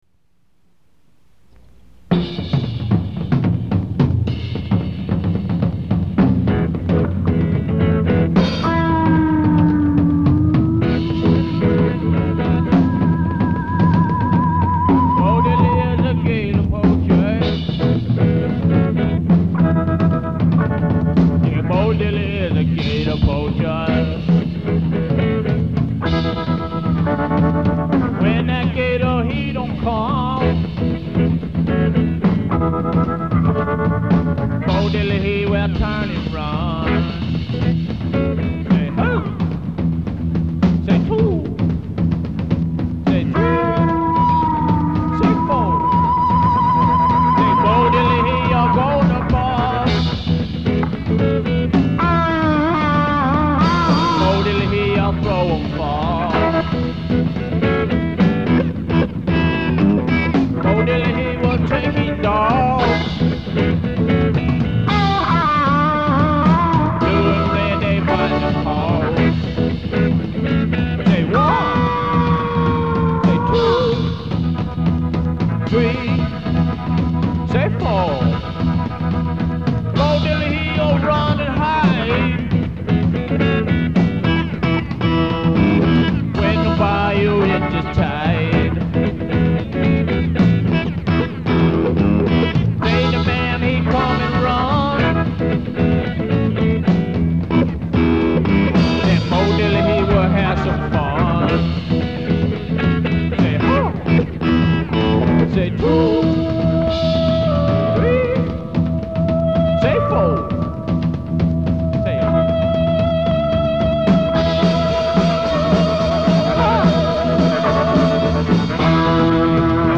1984 demo